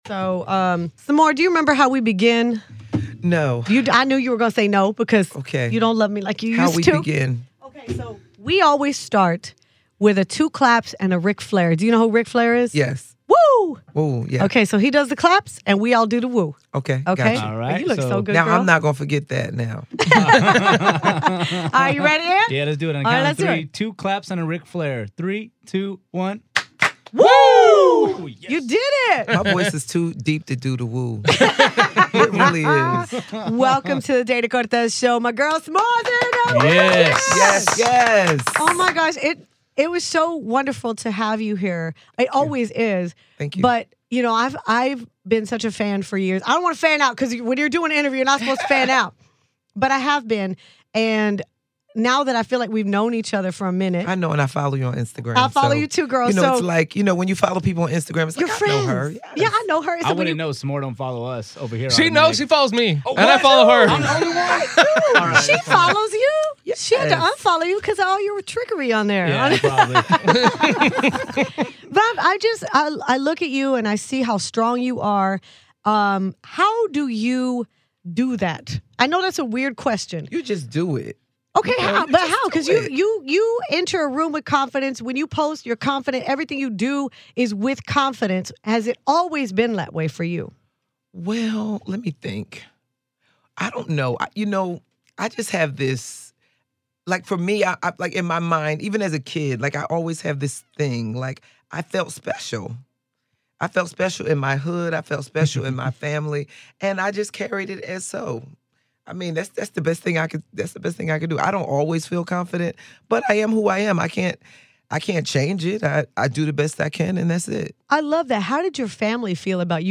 DCS Interviews Comedian Sommore